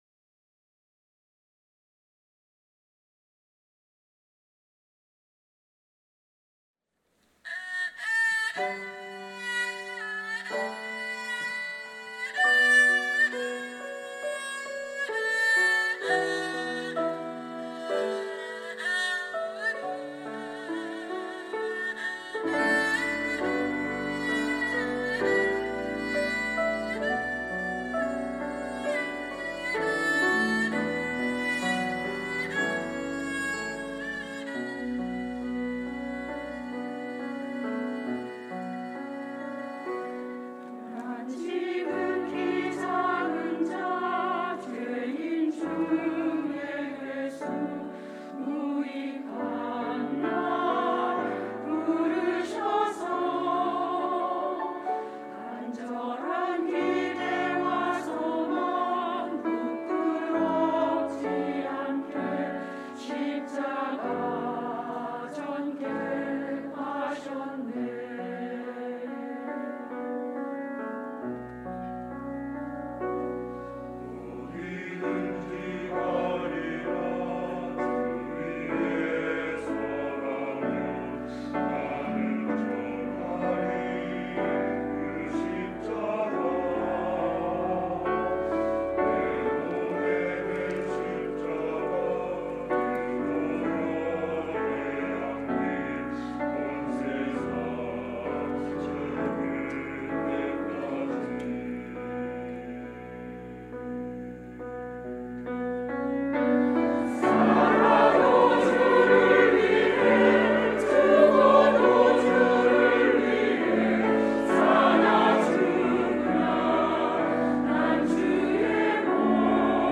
임마누엘